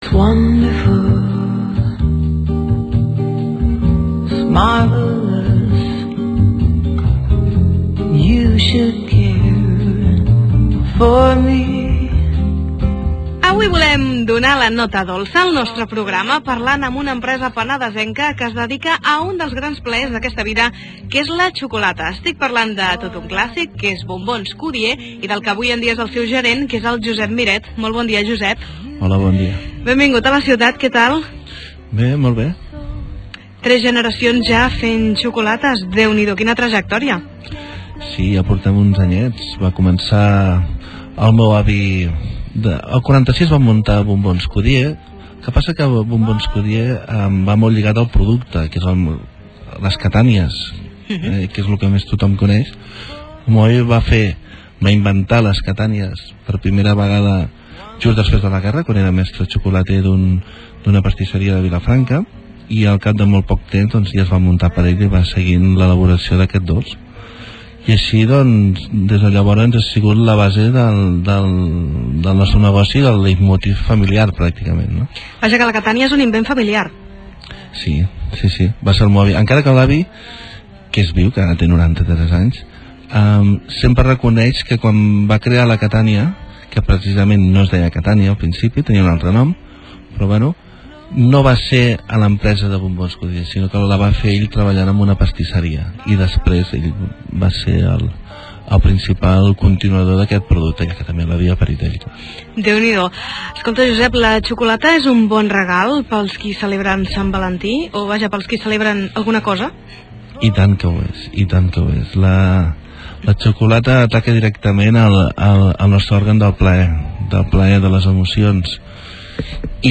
Si voleu sentir en primera persona la història, la filosofia i els secrets de les catànies no us perdeu aquesta entrevista. Entrevista a Onda Cero (Cliqueu per reproduïr-la) El regalo por excelencia por San Valentín es el chocolate.